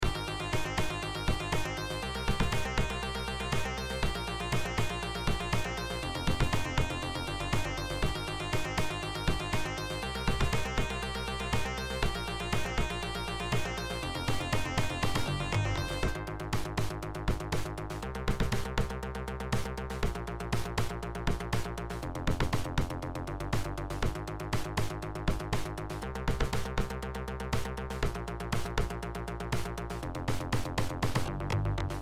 16-bit art and music